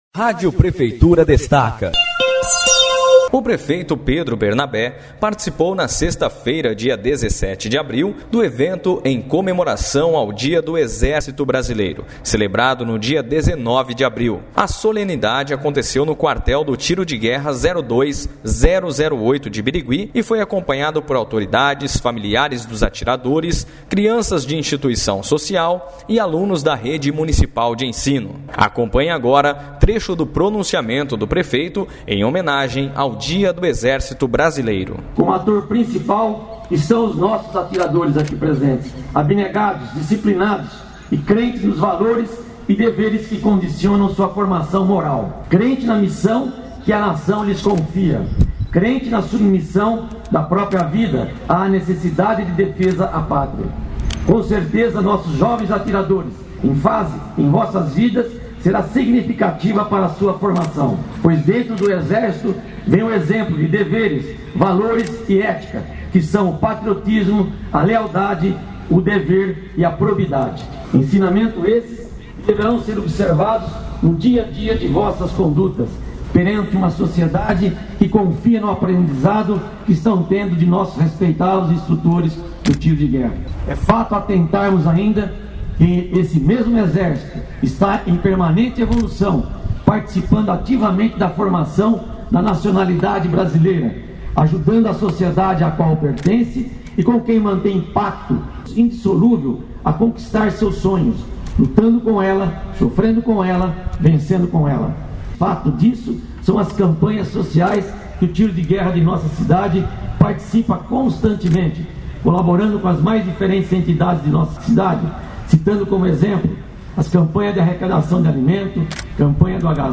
A solenidade ocorreu no quartel do Tiro de Guerra 02-008 de Birigui e foi acompanhada por autoridades, familiares dos atiradores, crianças de instituições sociais e alunos da rede municipal de ensino.
A Rádio Prefeitura esteve na solenidade, acompanhe trecho do pronunciamento do prefeito, Pedro Brenabé, em homenagem ao Exército Brasileiro.